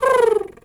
pigeon_2_call_05.wav